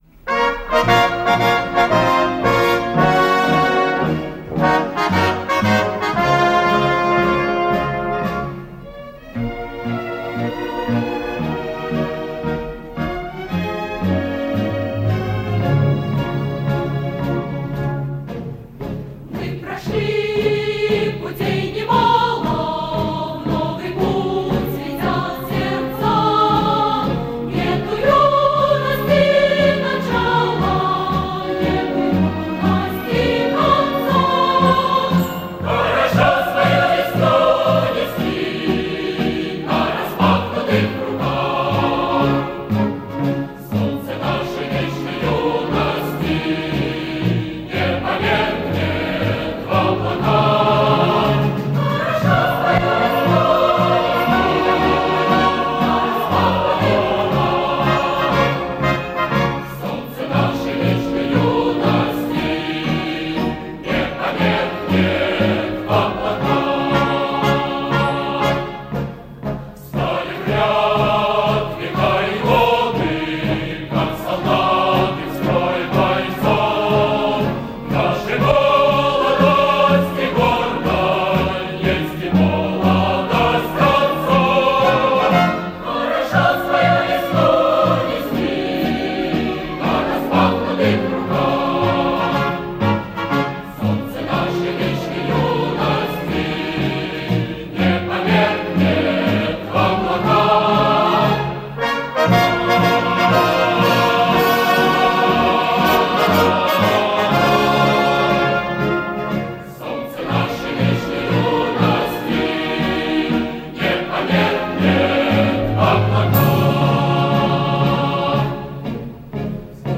Удачное, по-моему, концертное исполнение хорошей песни